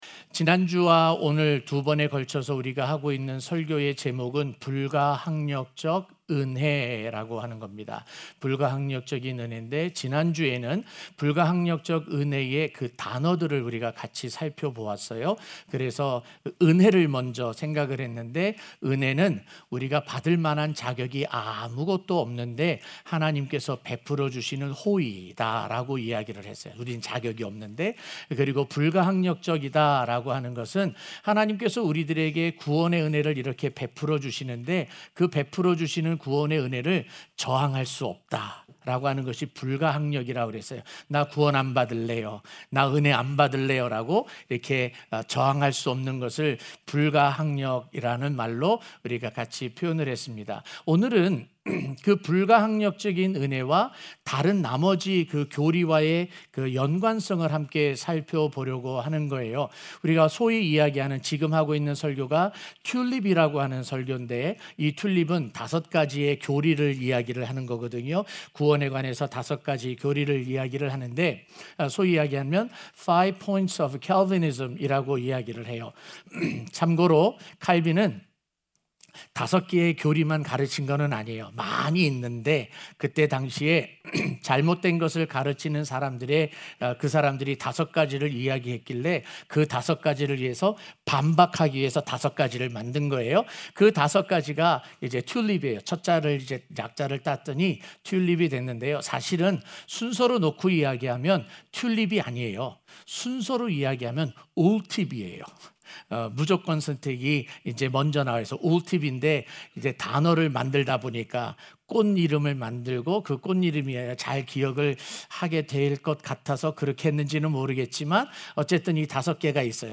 04-B-Irresistible-Grace-2-Sunday-Morning.mp3